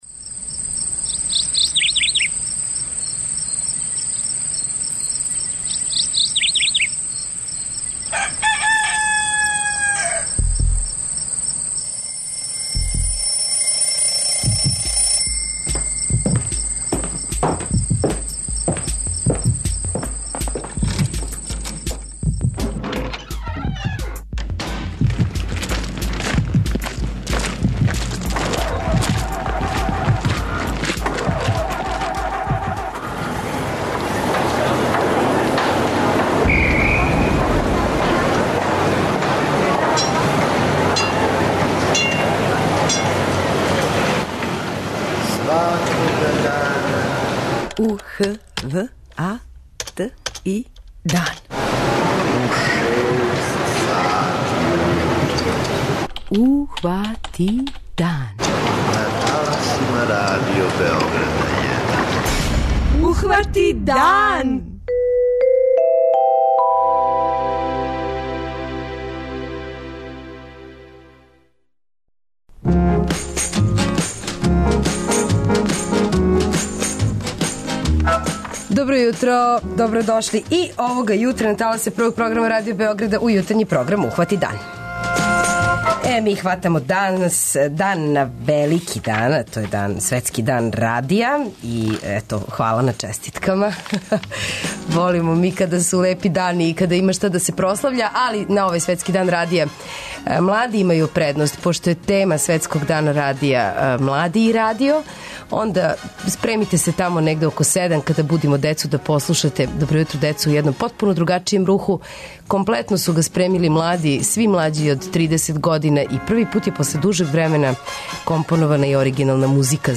После много година, компонована је и оригинална музика за ову емисију.
Анкетирали смо наше суграђане колико верују у то да овај датум има везе са лошим стварима које им се догађају.
преузми : 85.91 MB Ухвати дан Autor: Група аутора Јутарњи програм Радио Београда 1!